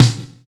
110 SNARE.wav